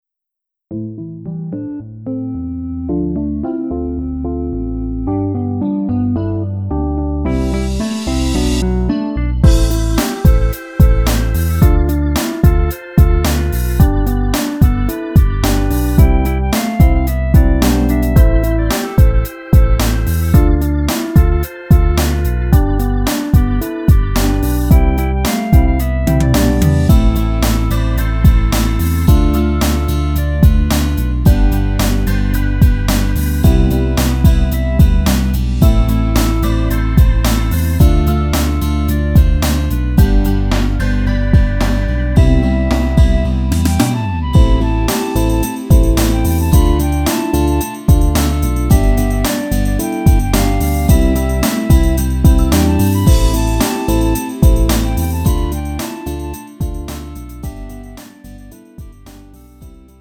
음정 원키 3:07
장르 가요 구분